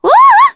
Aselica-Vox_Happy4_kr.wav